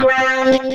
The voice that say 'ground' in the editor
SMM_Voice_Ground.oga.mp3